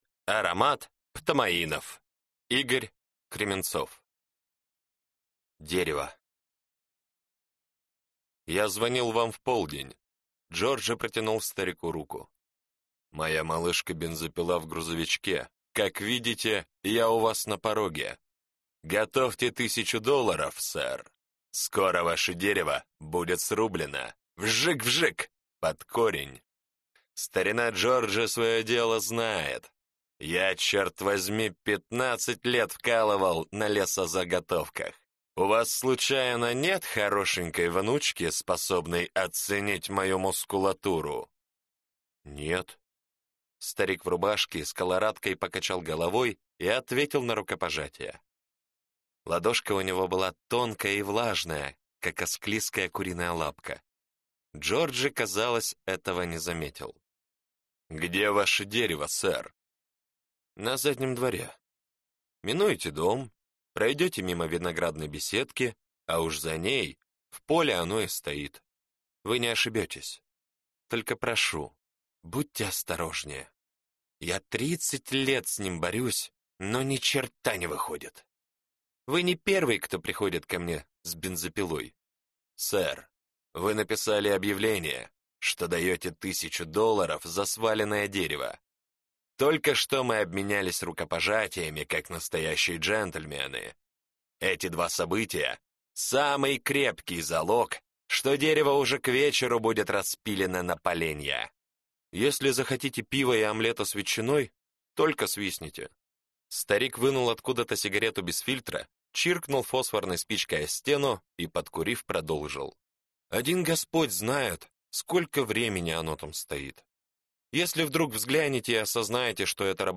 Аудиокнига Рассказы 9. Аромат птомаинов | Библиотека аудиокниг